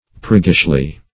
-- Prig"gish*ly, adv.